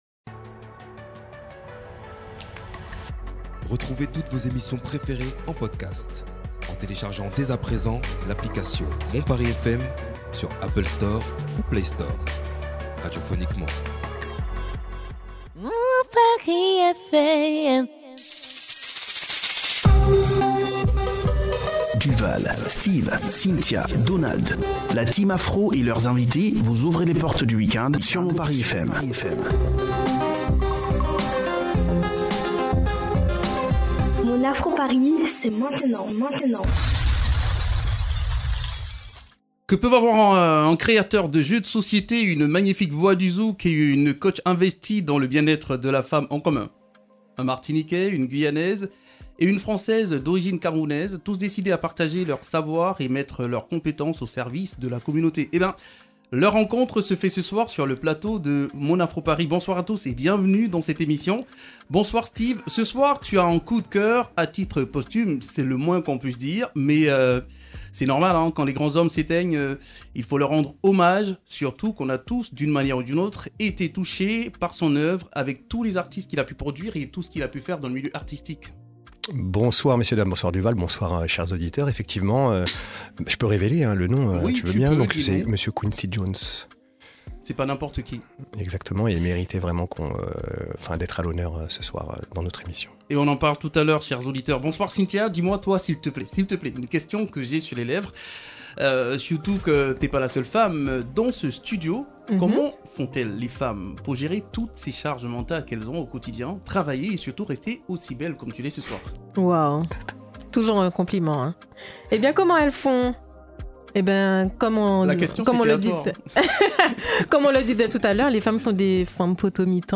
Tout cela dans une ambiance de rire, de blagues, de découvertes, de coup de cœur, de top de flop mais aussi de bons plans, pour un bon démarrage du Week end.